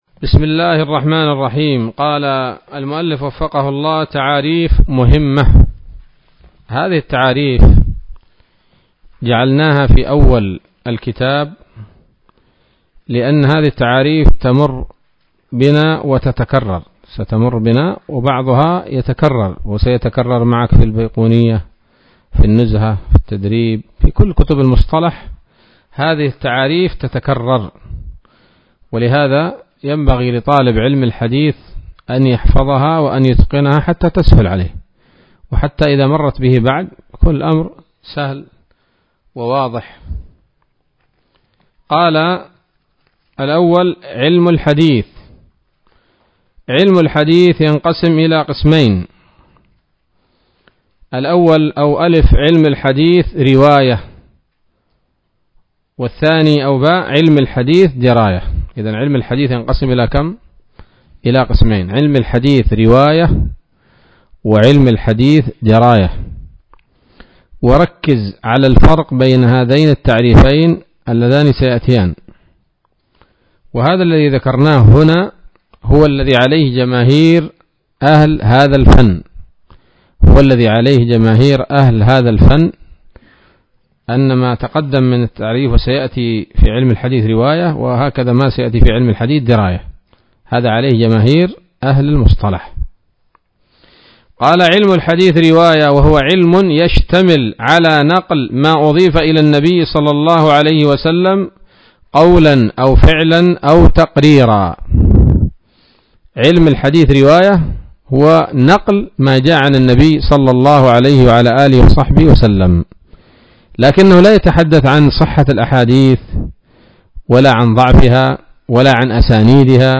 الدرس الثاني من الفتوحات القيومية في شرح البيقونية [1444هـ]